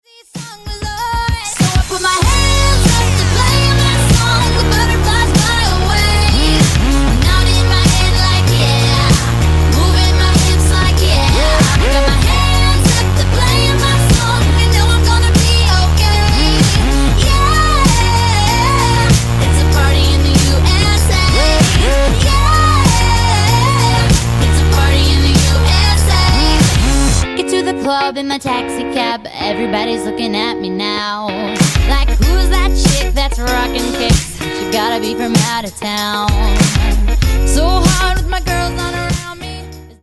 Веселая песня